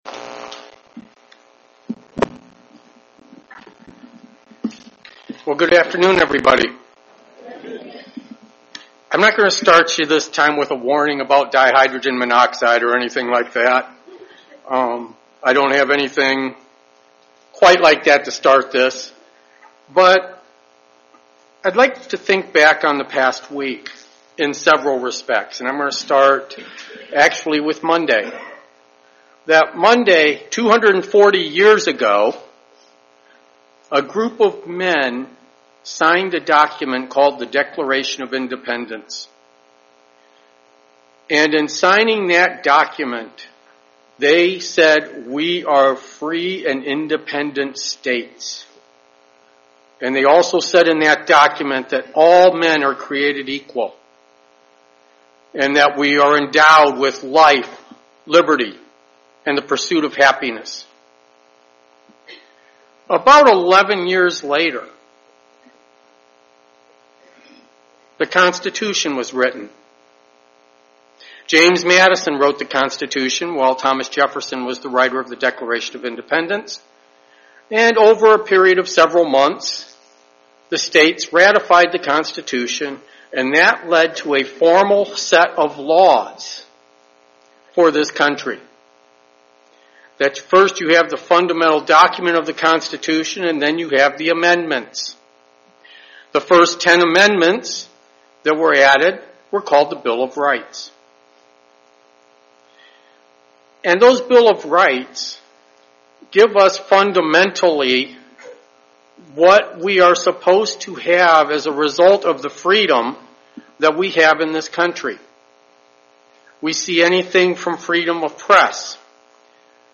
Many events have happened over this past week. This sermon will review some of those events and tie them in with God's law.